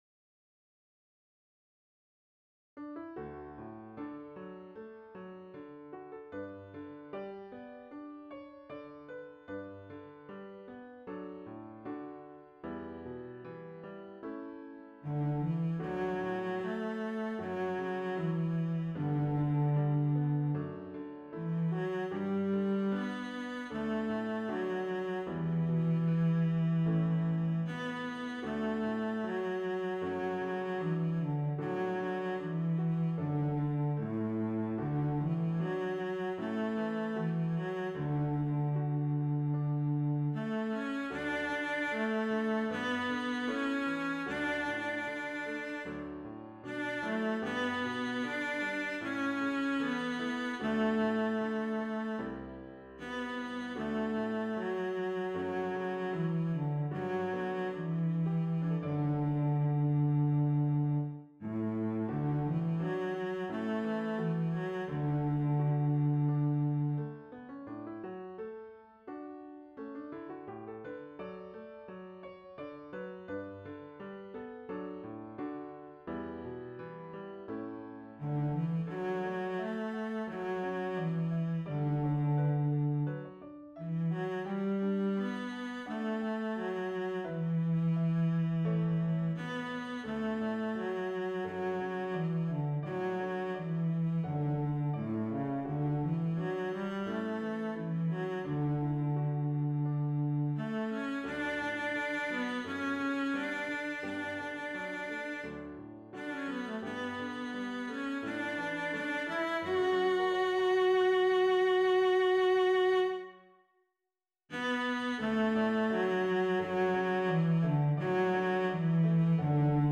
Intermediate Instrumental Solo with Piano Accompaniment.
Christian, Gospel, Sacred, Folk.
put to a flowing folk setting.